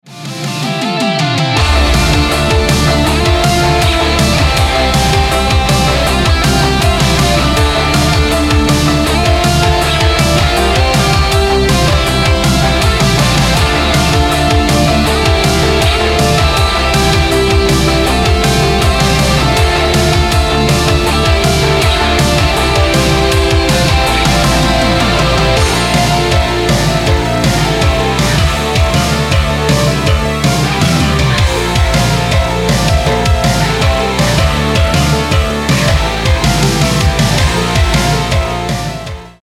• Качество: 320, Stereo
Драйвовые
без слов
Electronic Rock
synthwave
Retrowave
80-е
Крутой электронный рок в стилистике ретровейв